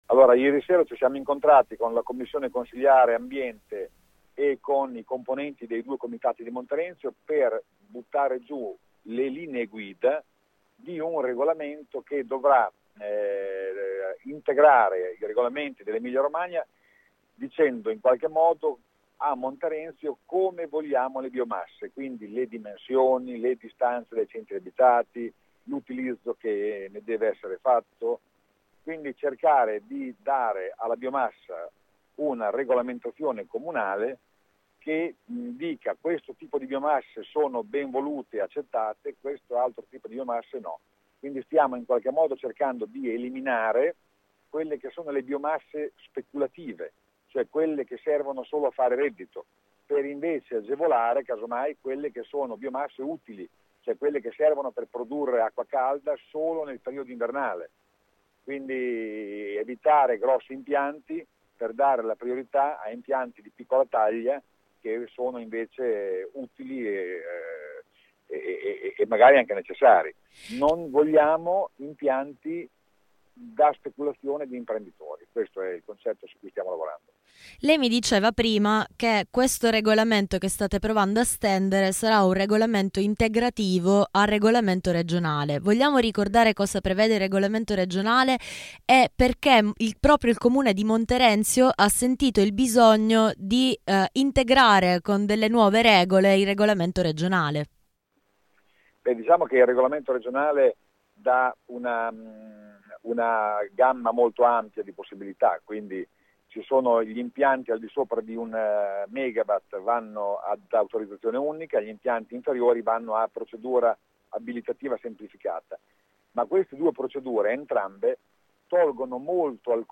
Ascolta Giuseppe Venturi, intervistato martedì 13 novembre